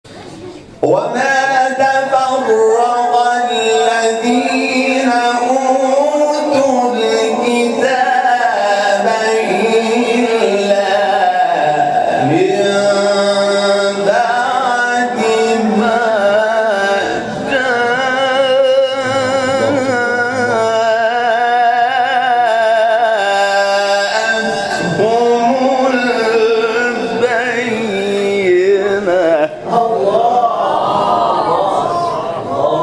گروه شبکه اجتماعی: جدیدترین مقاطع صوتی از تلاوت قاریان بنام و ممتاز کشور را که به تازگی در شبکه‌های اجتماعی منتشر شده است، می‌شنوید.
فرازی از حمید شاکرنژاد، سوره مبارکه بینه اجرا شده در مقام رست